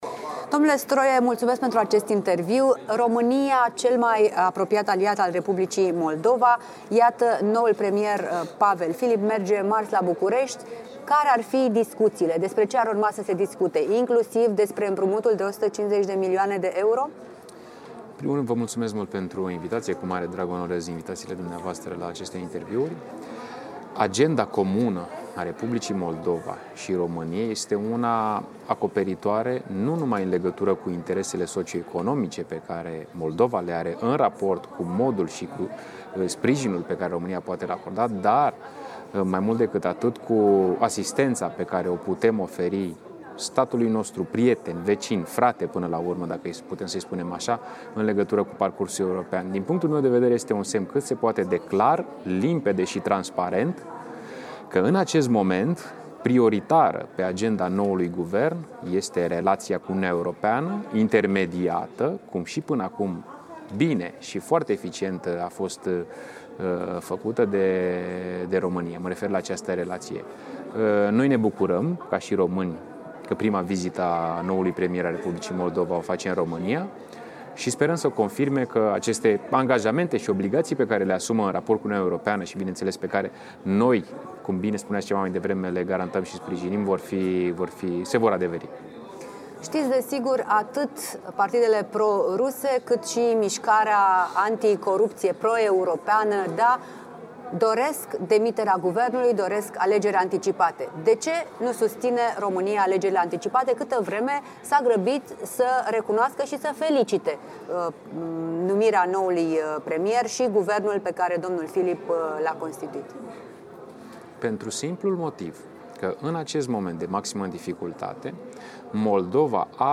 Interviu cu Ionuț Stroe